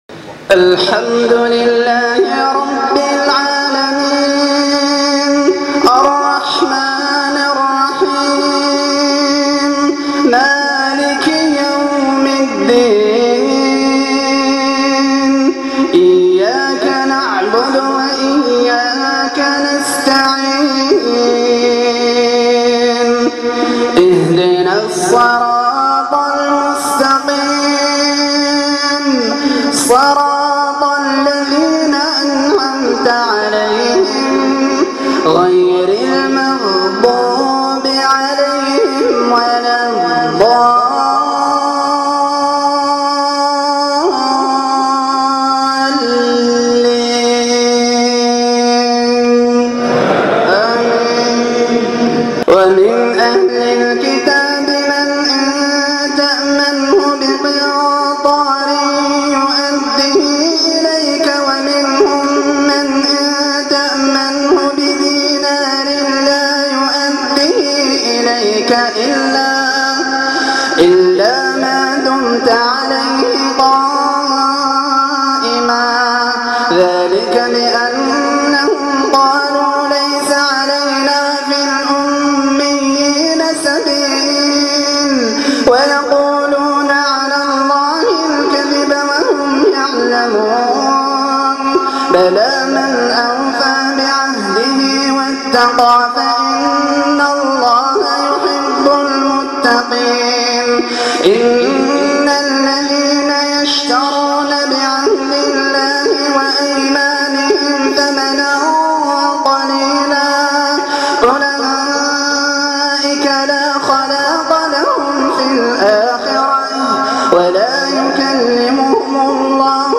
تلاوات تقشعر البدن وتدمع العين من صلاة التراويح